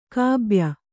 ♪ kābya